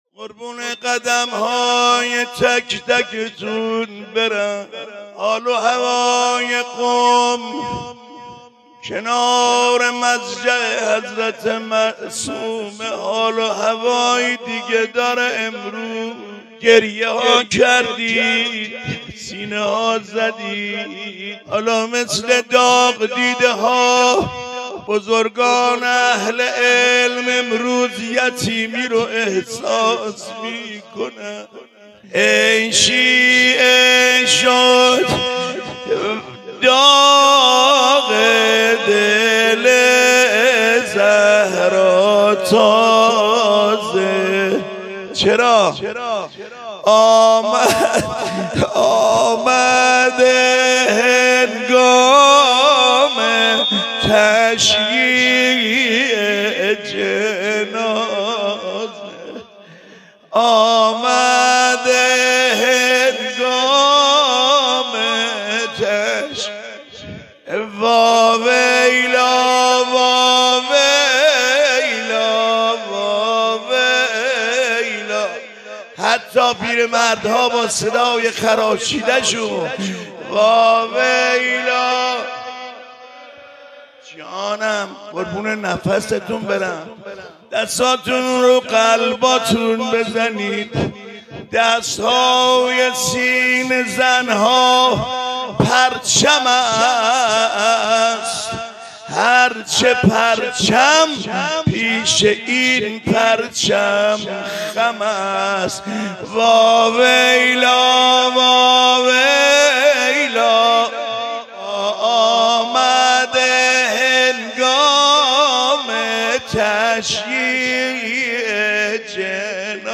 حاج علی انسانی | شهادت امام صادق علیه السلام | دفتر آیت الله وحید خراسانی | پلان 3